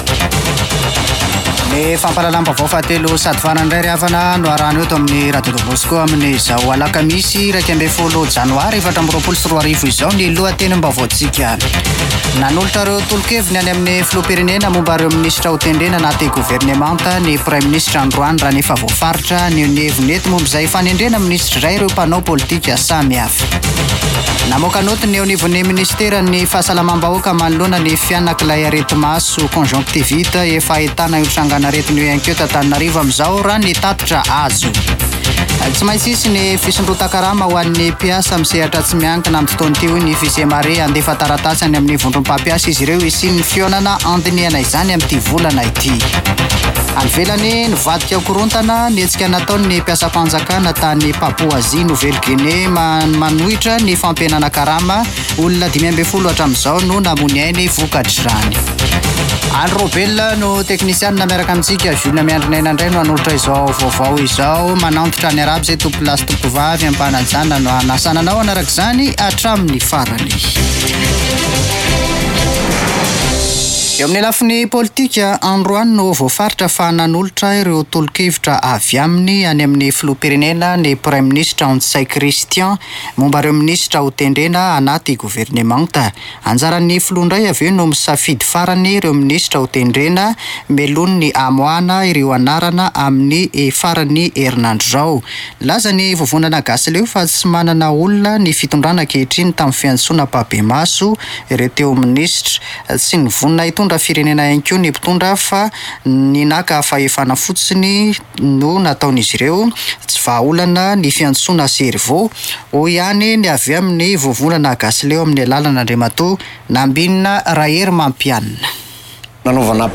[Vaovao hariva] Alakamisy 11 janoary 2024